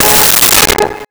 Car Horn 03
Car Horn 03.wav